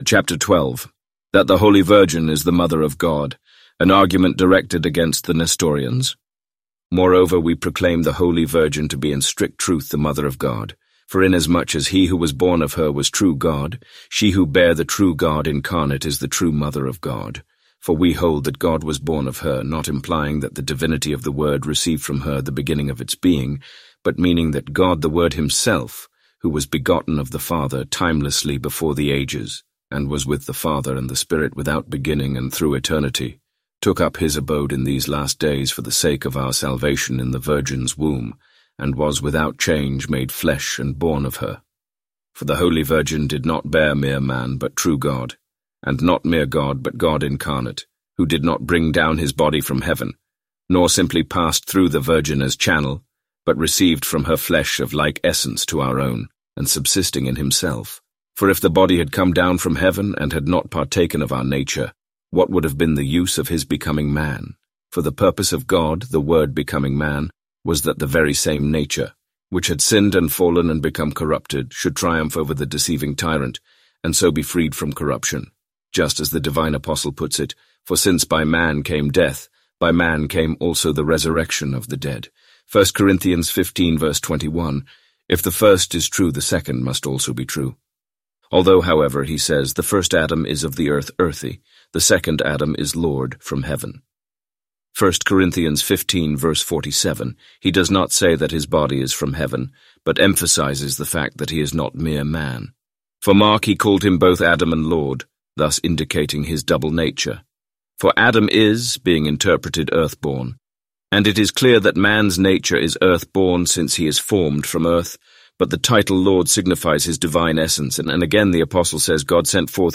Complete Audiobook Play Download Individual Sections Section 1 Play Download Section 2 Play Download Listening Tips Download the MP3 files and play them using the default audio player on your phone or computer.